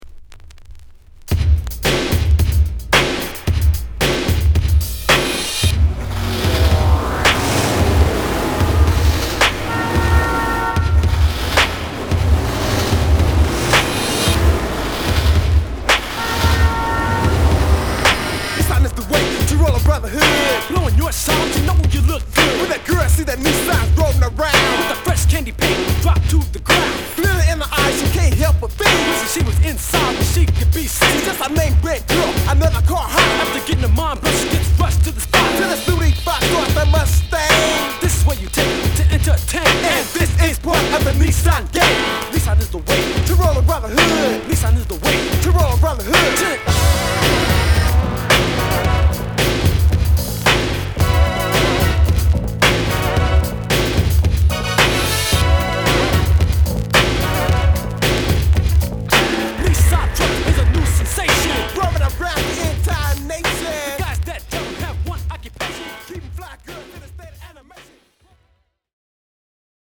ホーム HIP HOP BASS / ELECTRO 12' & LP E
日産ラップ!!